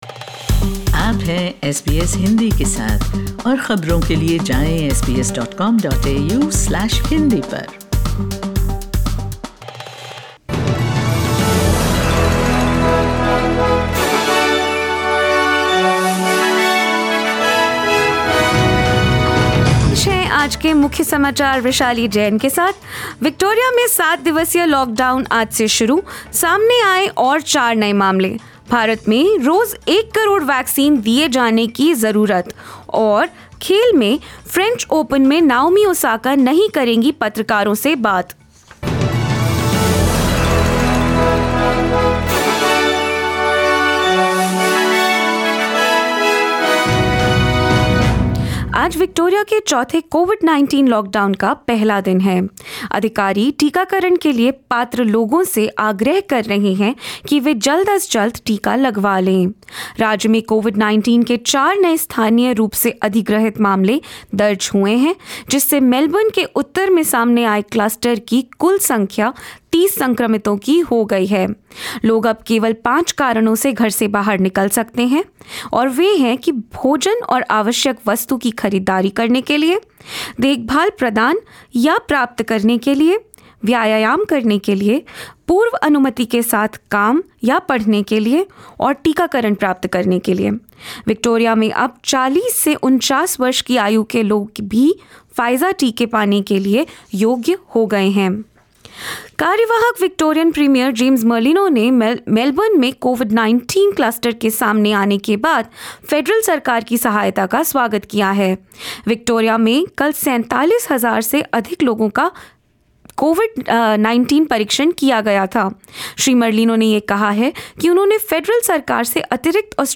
In this latest SBS Hindi News bulletin of Australia and India: Victoria enters day one of a seven-day Covid-10 lockdown to contain the outbreak; India needs 10 million vaccine doses a day to meet the current vaccine demand and more.